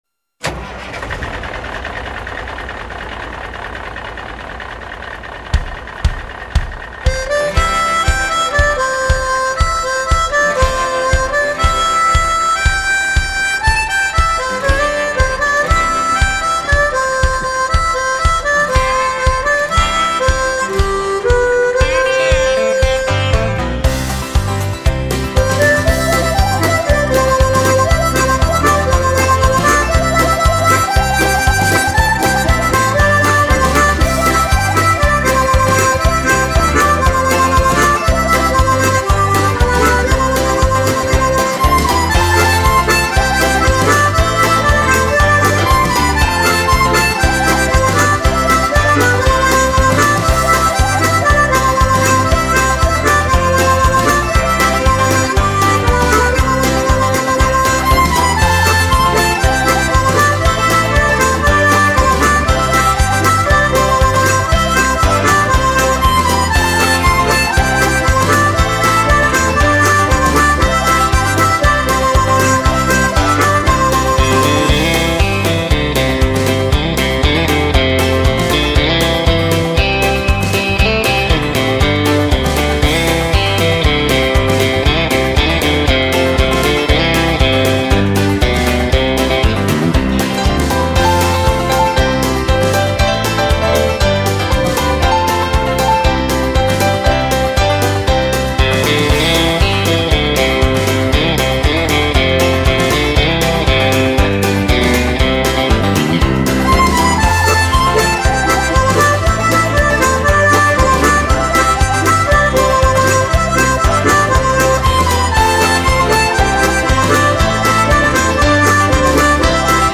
بسیار آرامش بخش هست
سازدهنی هارمونیکا